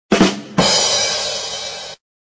Звуки бадум тсс
Бадум тсс барабанов